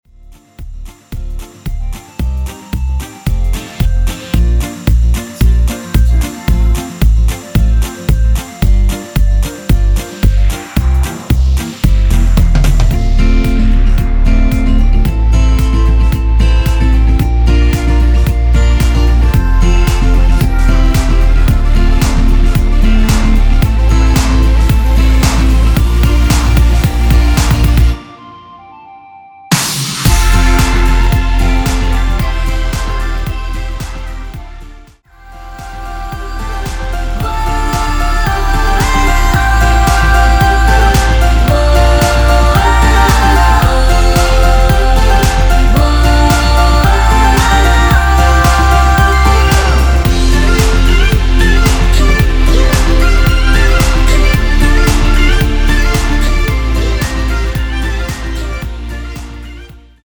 원키에서(+5)올린 멜로디와 코러스 포함된 MR입니다.
앞부분30초, 뒷부분30초씩 편집해서 올려 드리고 있습니다.